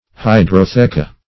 Search Result for " hydrotheca" : The Collaborative International Dictionary of English v.0.48: Hydrotheca \Hy`dro*the"ca\, n.; pl.
hydrotheca.mp3